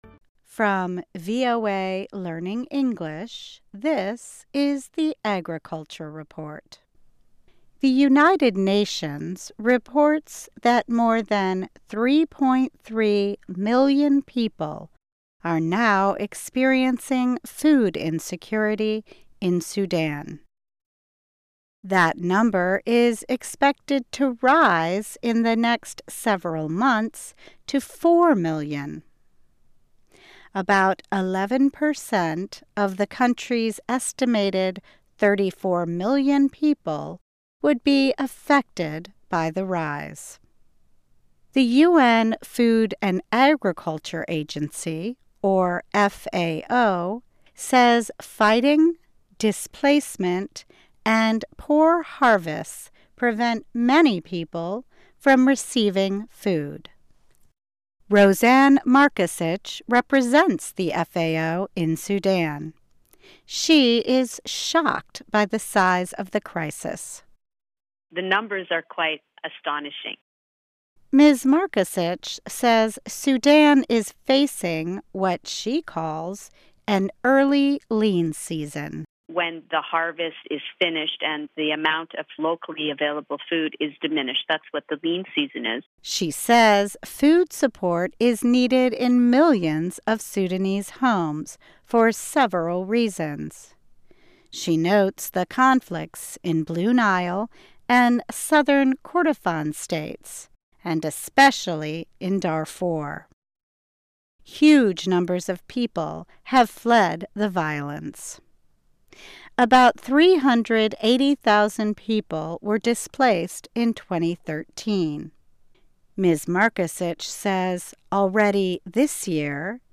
Learn English as you read and listen to a weekly show about farming, food security in the developing world, agronomy, gardening and other subjects. Our stories are written at the intermediate and upper-beginner level and are read one-third slower than regular VOA English.